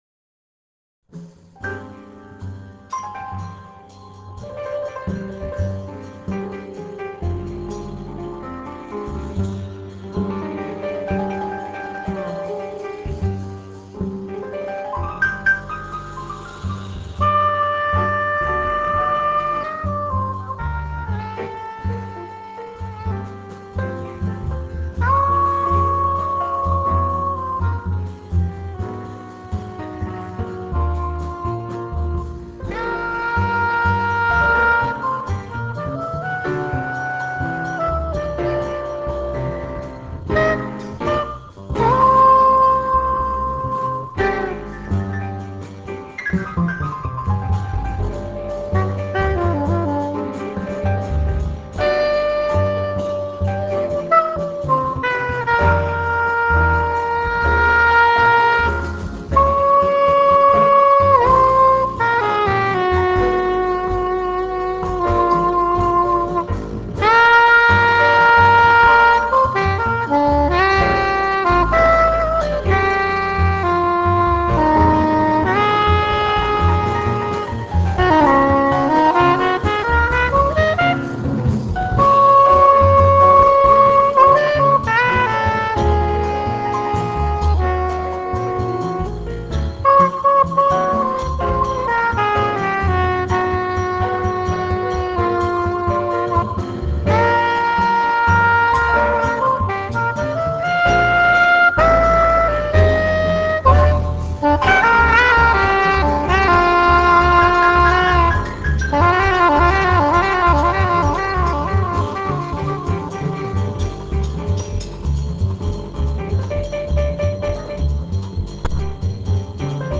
soprano saxophone
piano
bass
drums